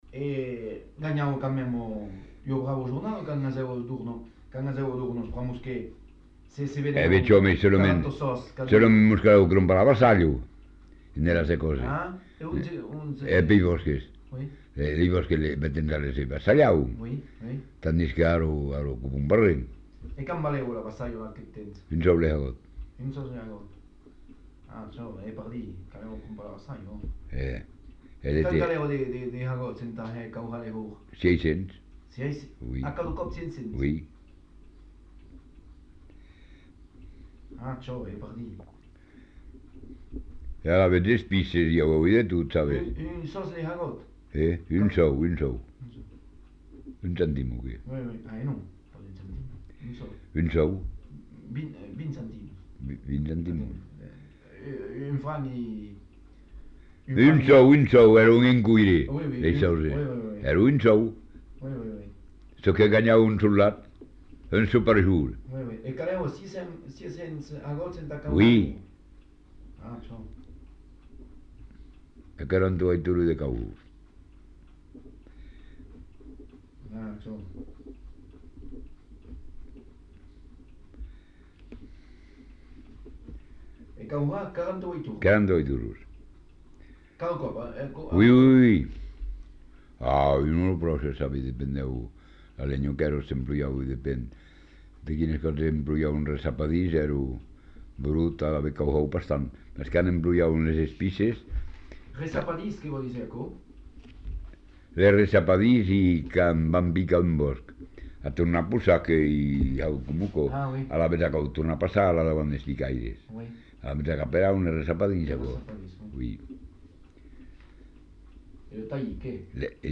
Lieu : Saint-Thomas
Genre : témoignage thématique